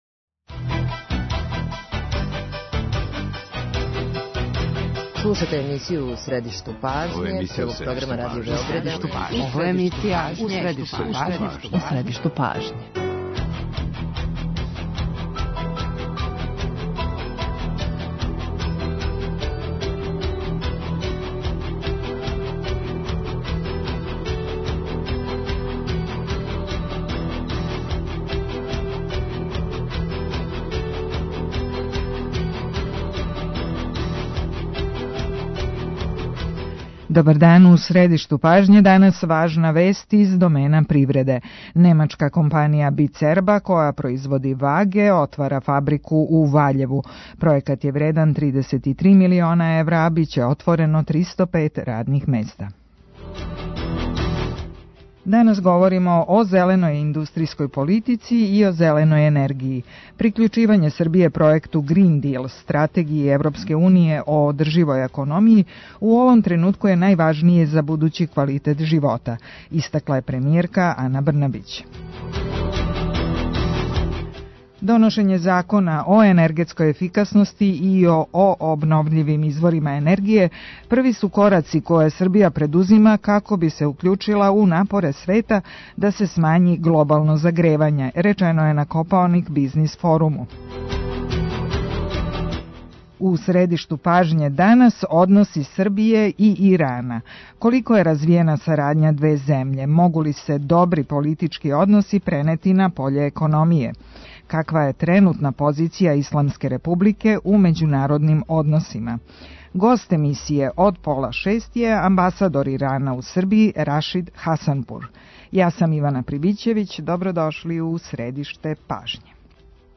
О односима Србије и Ирана, перспективама за њихово унапређење, али и ставовима Техерана према својој позицији у свету, за емисију У средишту пажње говори амбасадор Рашид Хасанпур.